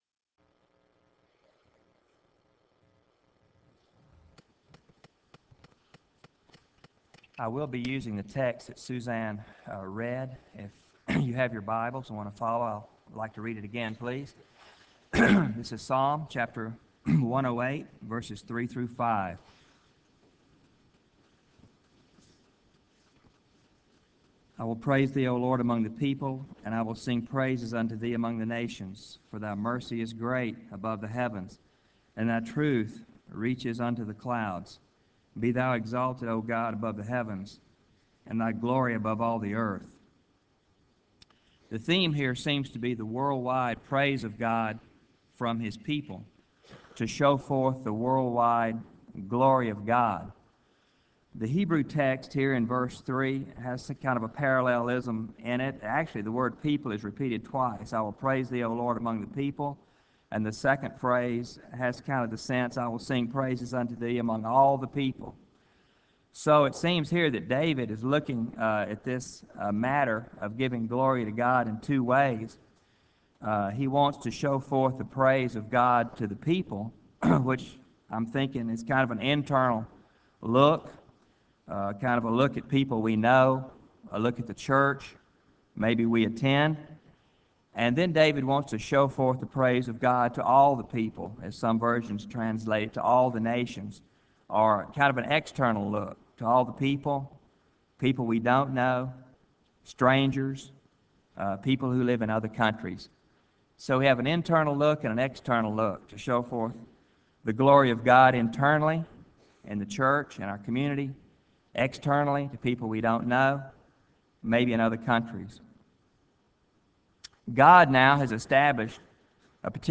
Chapel Service: GO Week
Address: Psalm 108:3-5